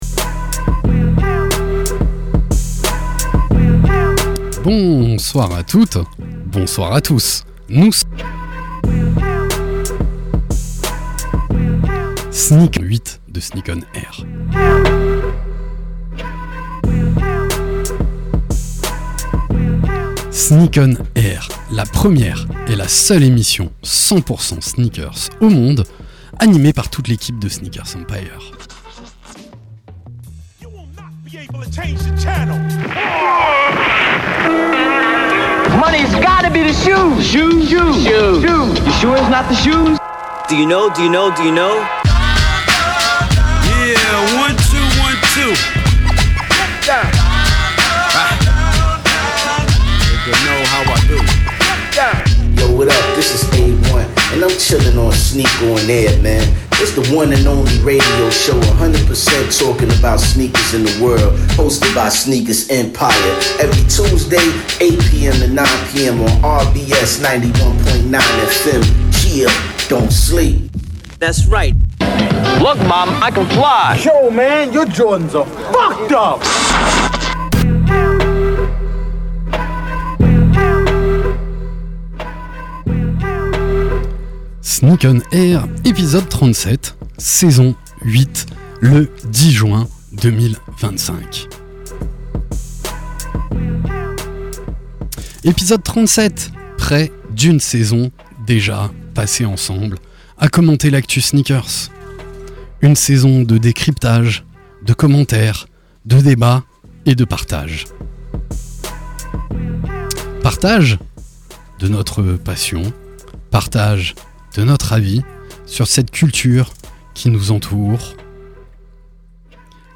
Sneak ON AIR, la première et la seule émission de radio 100% sneakers au monde !!! sur la radio RBS tous les mardis de 20h à 21h.
Pour cet épisode, nous vous proposons une heure de talk, d’actus, et de débats autour des faits marquants de l’univers de la sneaker avec tous nos chroniqueurs.